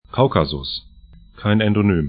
Pronunciation
Kaukasus 'kaukazʊs Kavkasionis kaf'ka:zĭonɪs geor. Gebirge / mountains 42°35'N, 43°27'E